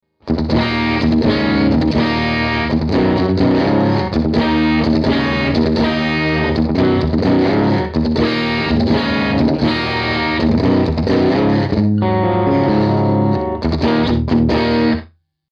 The audio clips were done by yours truly in my studio. Some of the amps I own and some were recorded using amp simulators/modeling.
6: Vox AC30 top boost, full up crunchy. Basic open chord progression with suspended voicings
Pros: The spiky tendencies are smoothed out. Nice light crunchy tone that has great chord definition and works well in most pop styles.
Cons: Not enough distortion for the gain hungry out there.
hfx3_6_vox_ac30_top_boost_full_up_crunchy.mp3